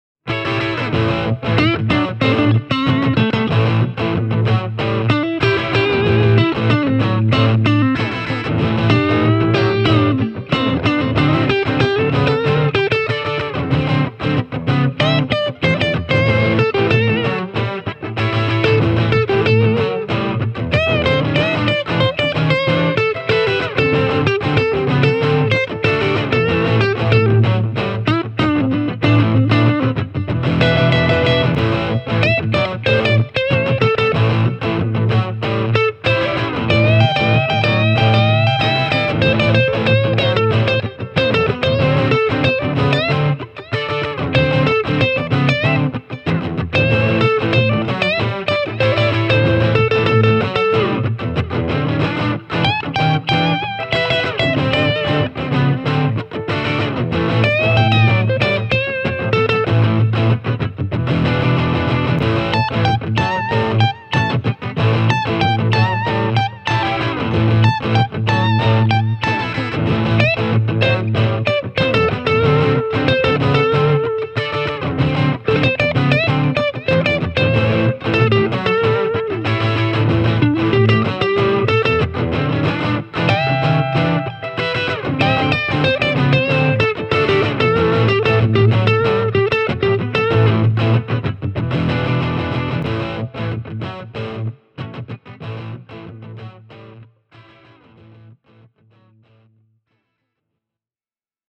Demo track number one features two rhythm guitar tracks – a Fender Stratocaster (stereo left) and a Gibson Les Paul Junior (right) – as well as a Hamer USA Studio Custom on lead duty:
Delay and reverb added during mixdown.
Recorded with a Shure SM57.